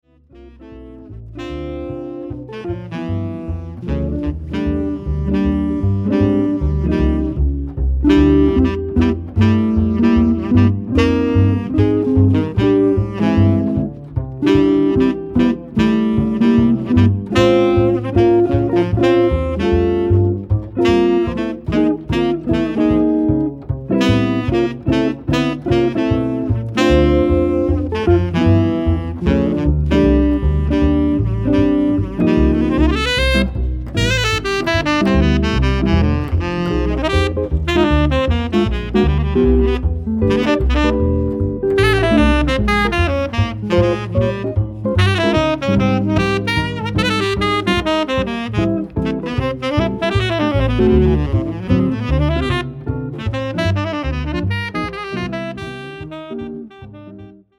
Trio
Saxophon/Floete*, Gitarre & Bass